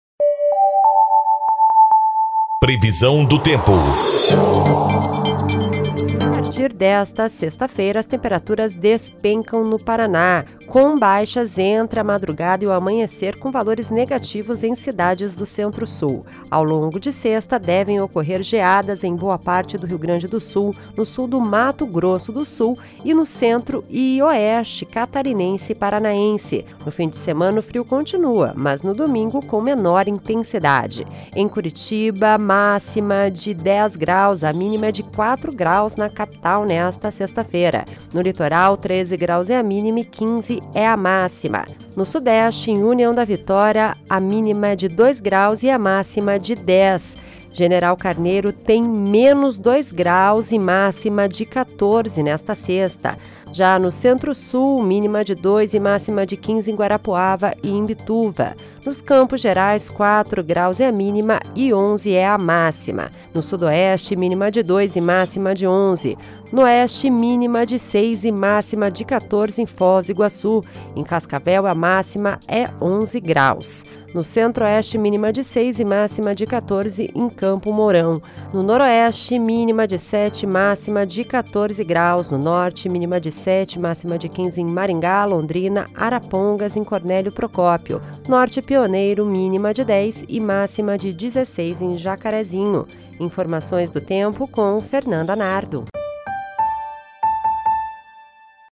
Previsão do Tempo (19/08)